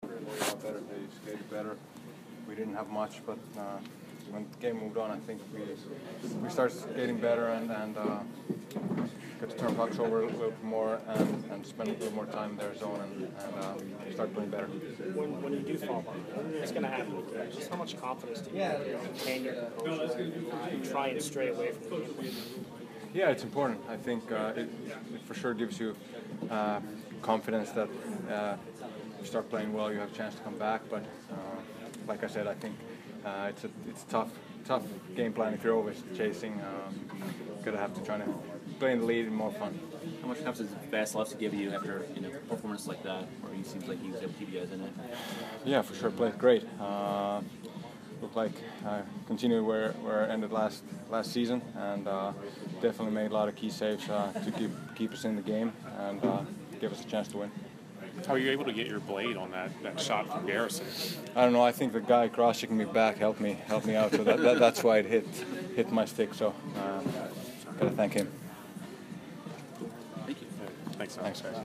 Filppula Post Game 10/15